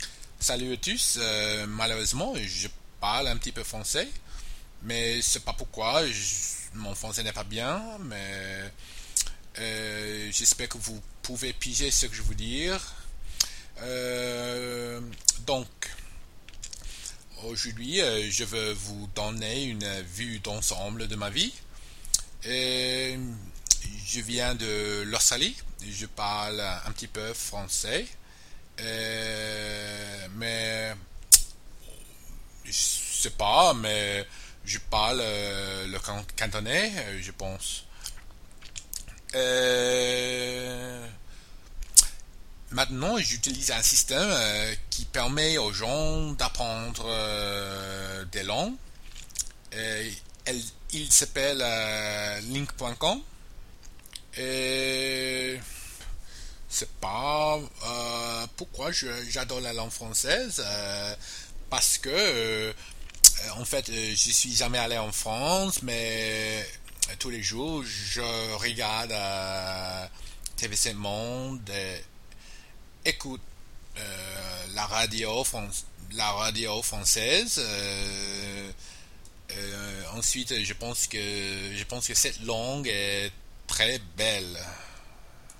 The following was impromptu and randomly outputted from my head with no structure:
You do speak with some accent but it's not as heavy as they told you, at least to me.